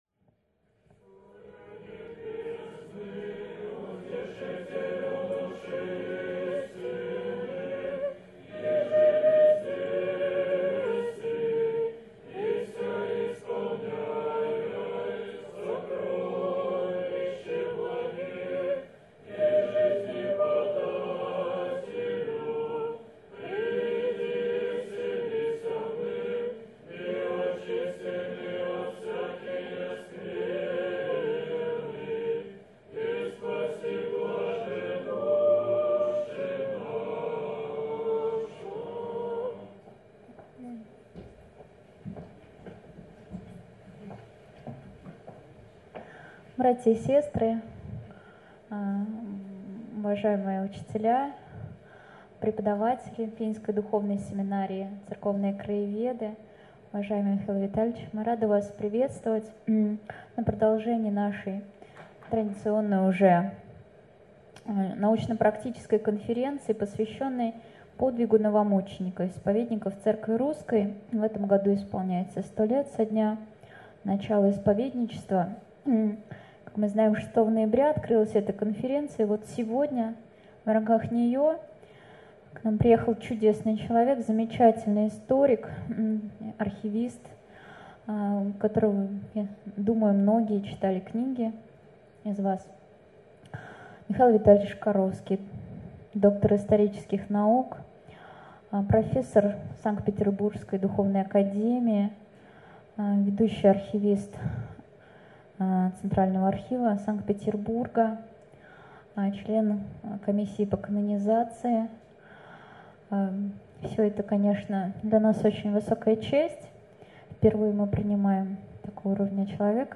В конце страницы вашему вниманию представлена аудиозапись выступления докладчика.